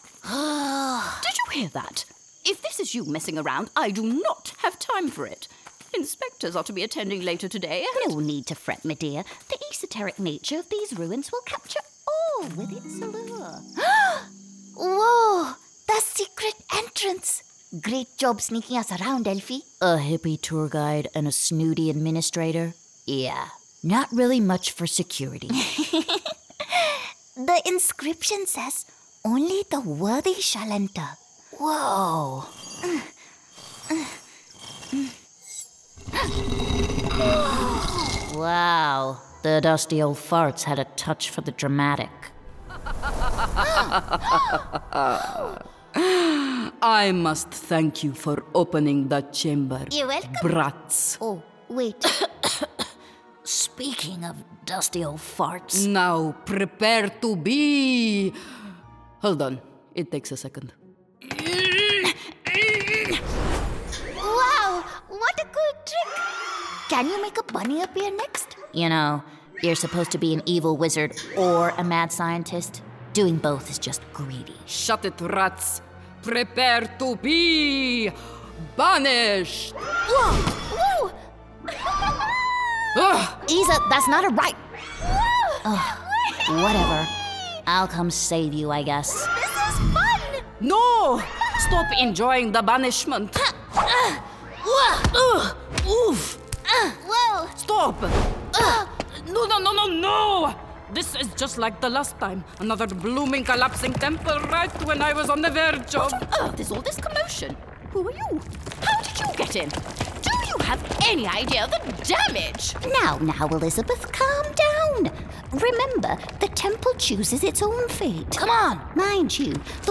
Intelligent, smooth, neutral and warm
Character Reel